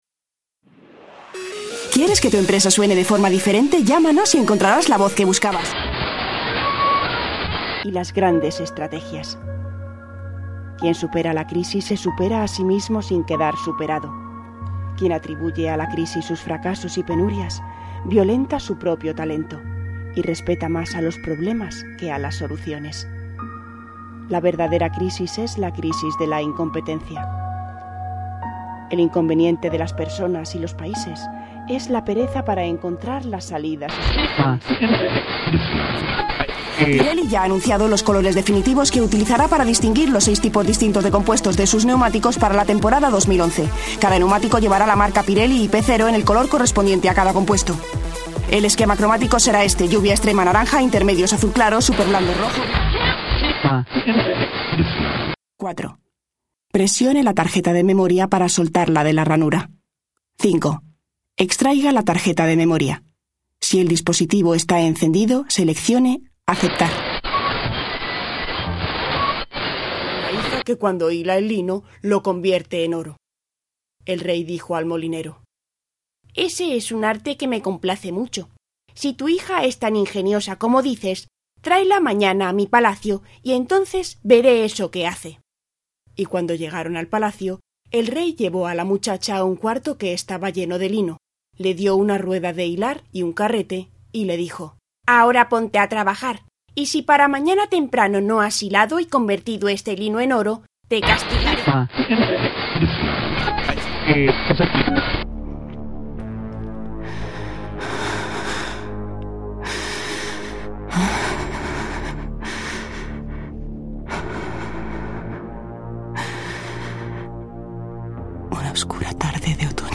kastilisch
Sprechprobe: Industrie (Muttersprache):